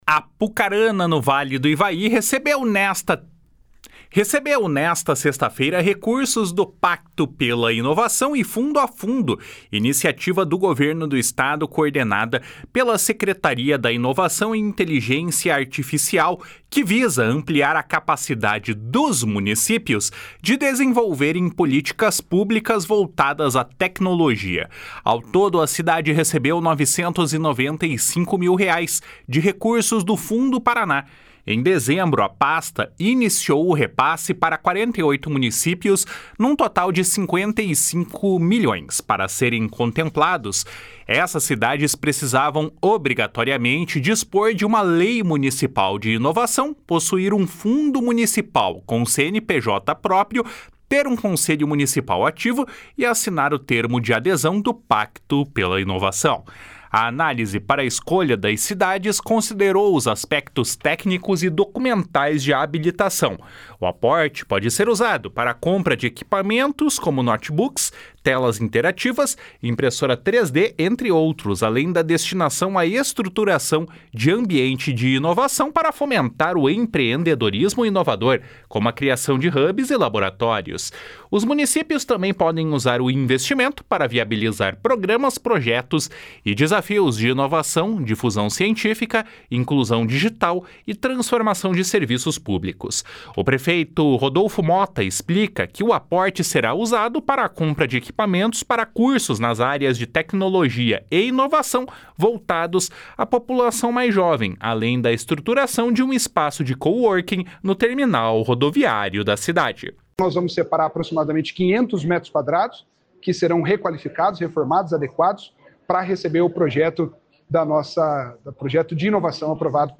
O prefeito Rodolfo Mota explica que o aporte será usado para a compra de equipamentos para cursos nas áreas de tecnologia e inovação voltados à população mais jovem, além da estruturação de um espaço de coworking no Terminal Rodoviário da cidade. // SONORA RODOLFO MOTA //